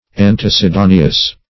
Search Result for " antecedaneous" : The Collaborative International Dictionary of English v.0.48: Antecedaneous \An`te*ce*da"ne*ous\, a. [See Antecede .] Antecedent; preceding in time.